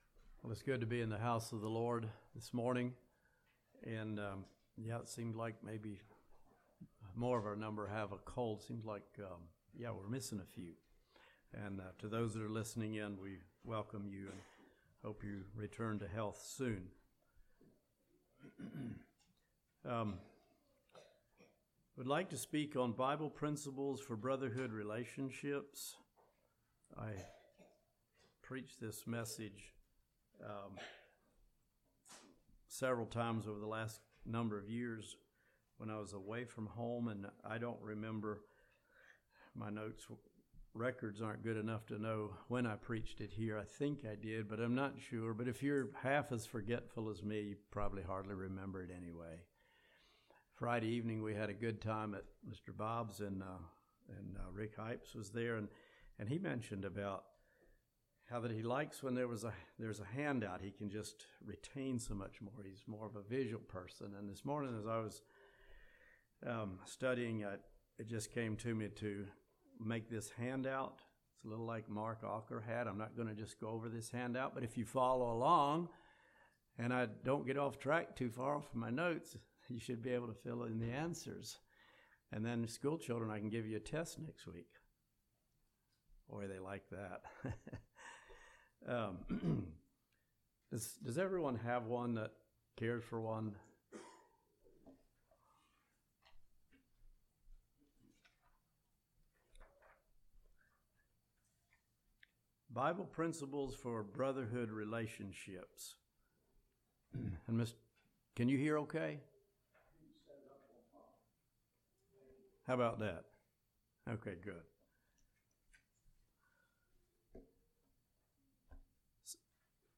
A practical message on how we should relate as a brotherhood.